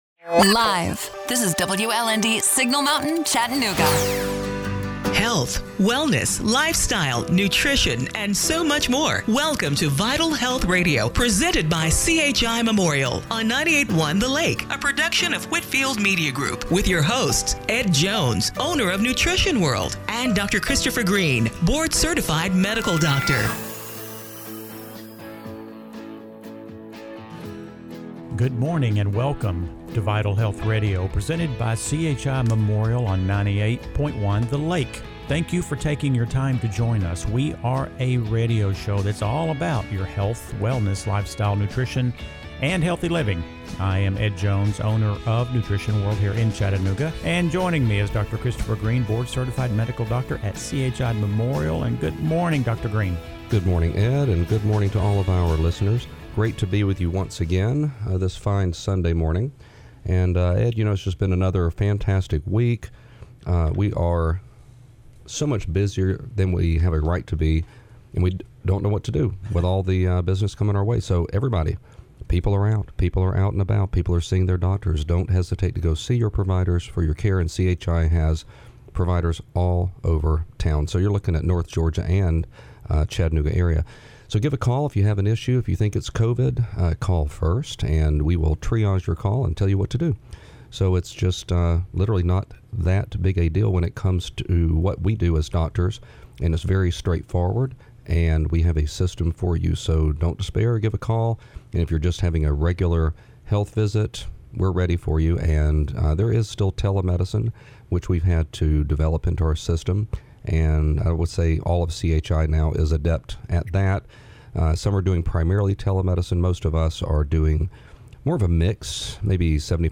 September 27, 2020 – Radio Show - Vital Health Radio